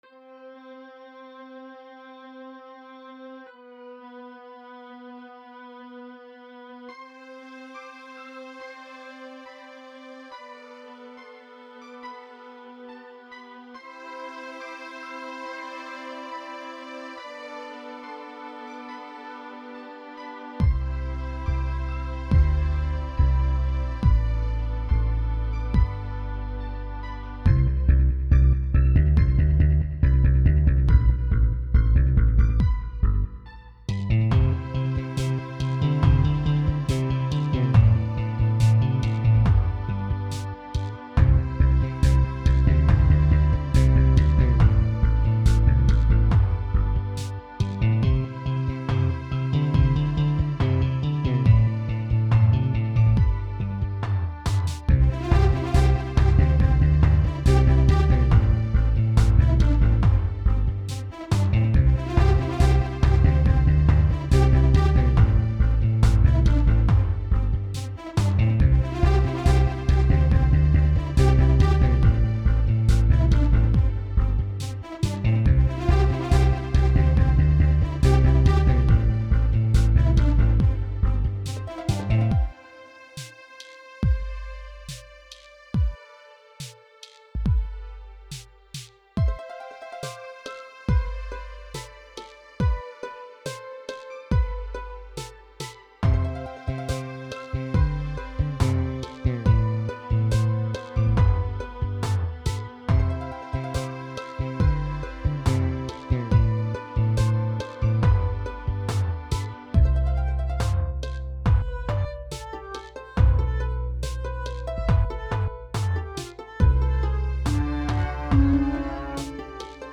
"Eclipse" Instrumental